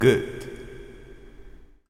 あと、何となくエコーが掛かってます。